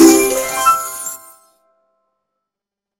Win SFX .mp3